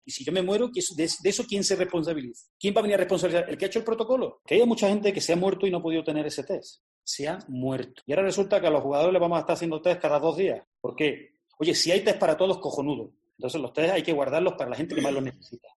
Escucha aquí al entrenador del Rayo Vallecano, Paco Jémez